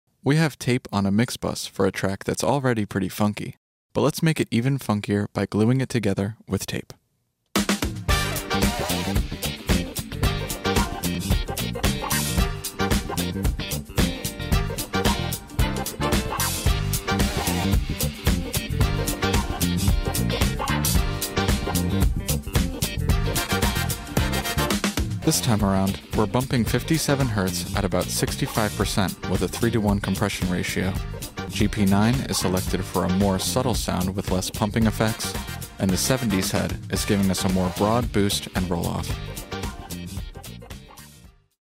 This is the sound of the real analog components within our APB creating the sound of tape saturatioj on a funky mix using our latest APB plug-in, TAPE.